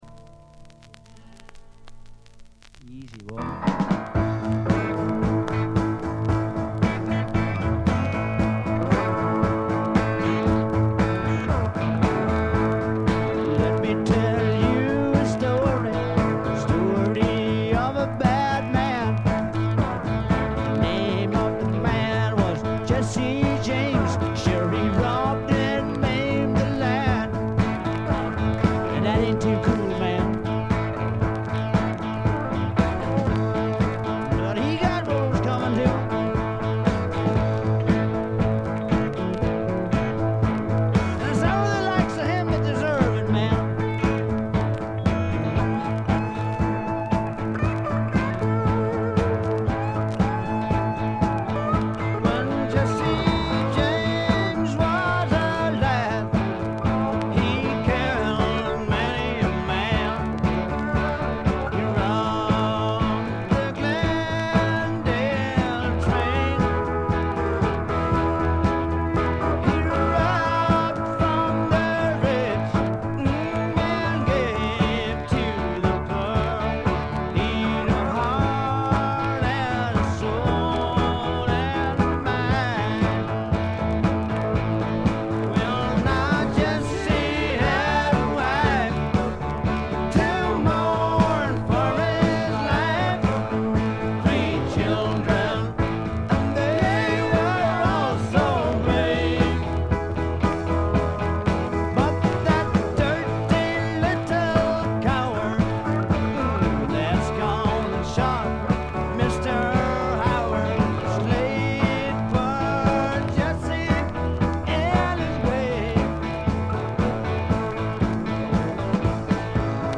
バックグラウンドノイズ、チリプチ、プツ音等多め大きめですが、鑑賞を妨げるほどではなく普通に聴けるレベルと思います。
内容は笑っちゃうぐらい売れなさそうな激渋スワンプ。
試聴曲は現品からの取り込み音源です。